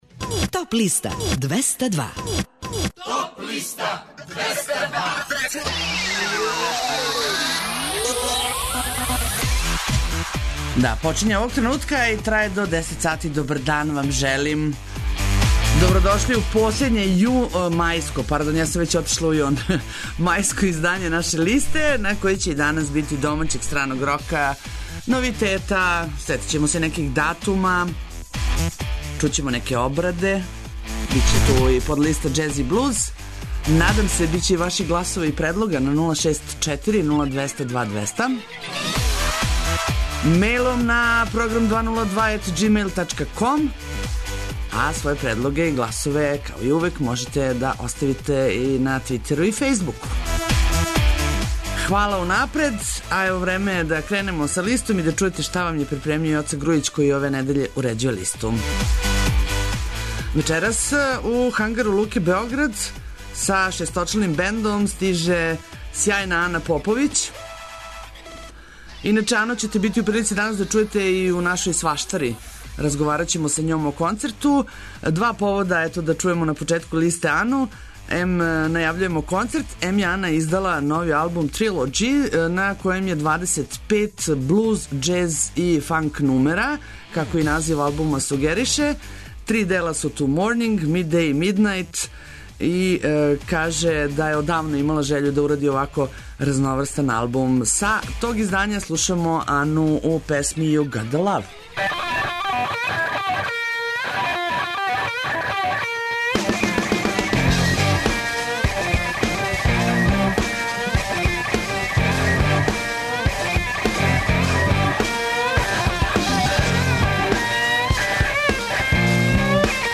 Најавићемо актуелне концерте у овом месецу, подсетићемо се шта се битно десило у историји музике у периоду од 30. маја до 3. јуна. Емитоваћемо песме са подлиста лектире, обрада, домаћег и страног рока, филмске и инструменталне музике, попа, етно музике, блуза и џеза, као и класичне музике.